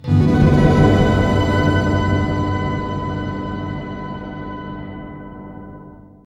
SI2 WOBBL00L.wav